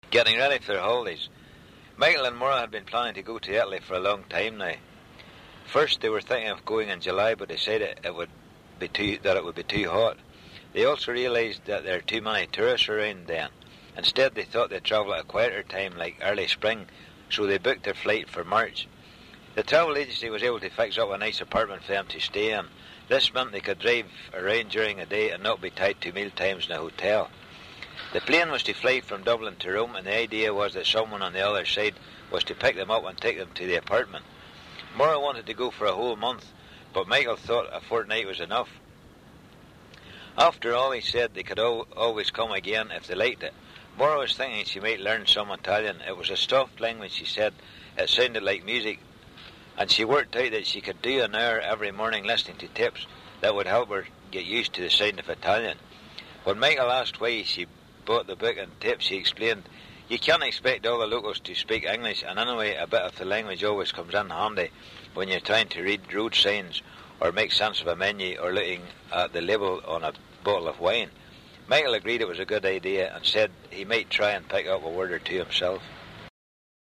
The informants all read a standard (fictitious) text which I offered to them when doing recordings.
Local rural speaker from Co. Antrim, north-east of Ulster (Northern Ireland)